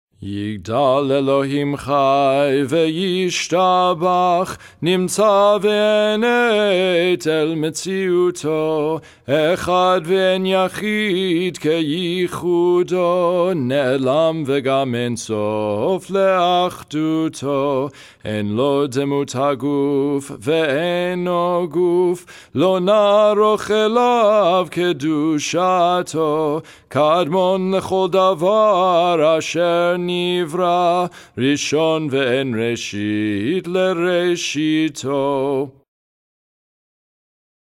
Shabbat Evening (Lower Voice)
15_yigdal__congregational_melody_.mp3